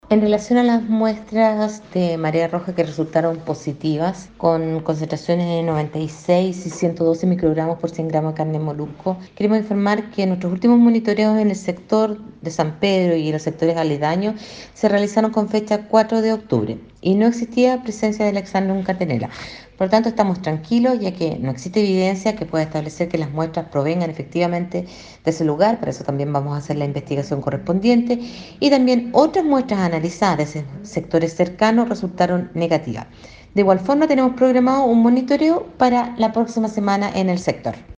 En tanto, la seremi de Salud (S) Marcela Cárdenas, dijo que luego de los últimos muestreos realizados en isla San Pedro y alrededores, no se encontraron indicios de que exista “marea roja” en la zona.